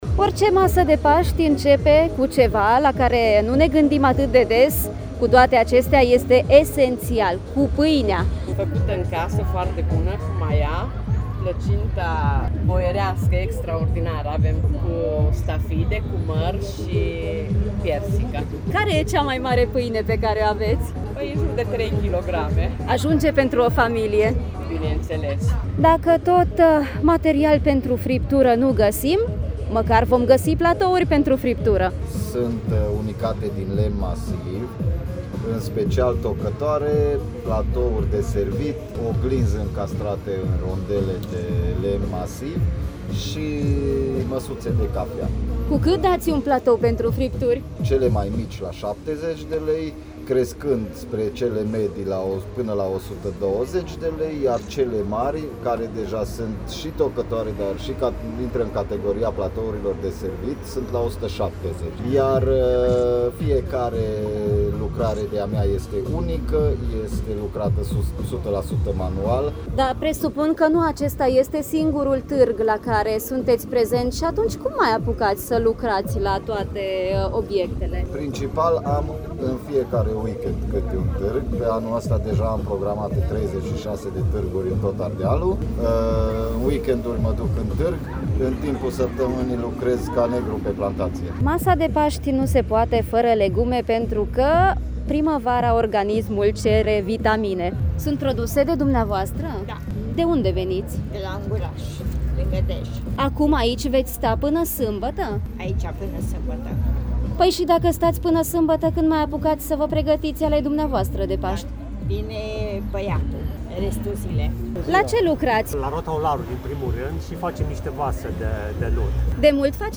Am făcut o vizită la târg, în căutare de inspirație pentru masa de Paști.